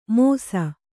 ♪ mōsa